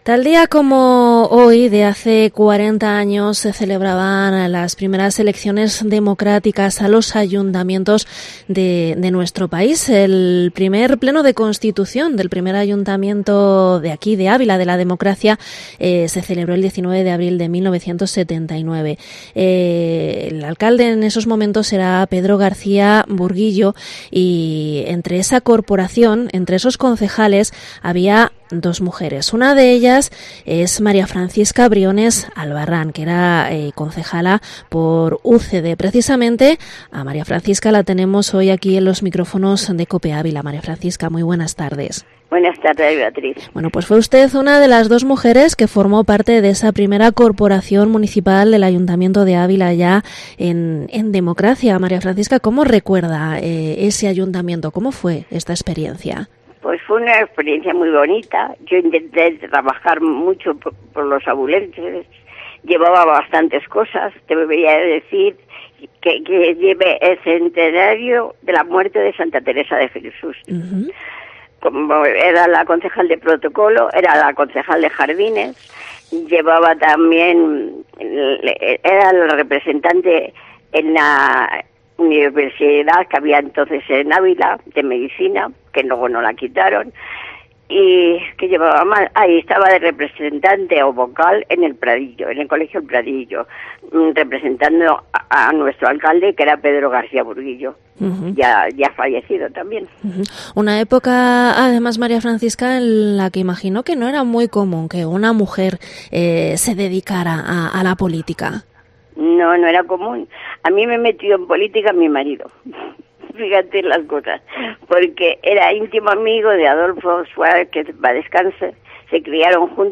Entrevista María Francisca Briones Albarrán, concejal de la primera corporación democrática del Ayuntamiento